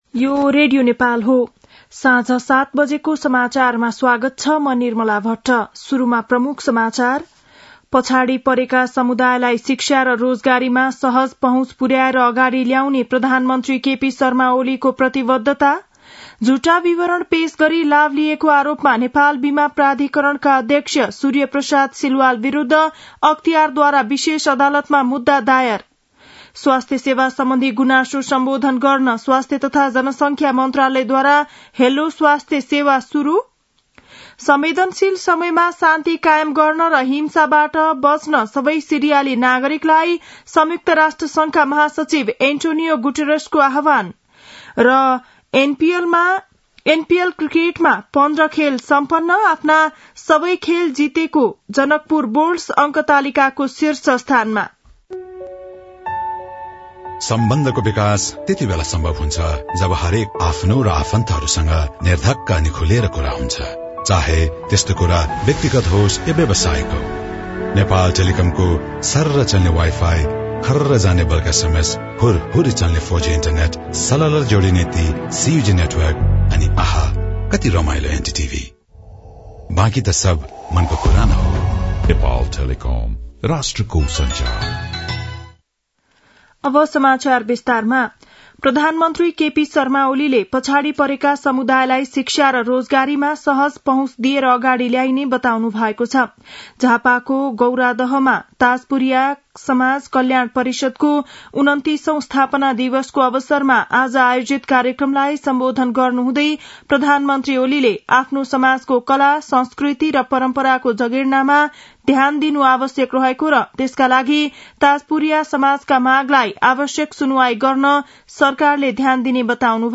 बेलुकी ७ बजेको नेपाली समाचार : २५ मंसिर , २०८१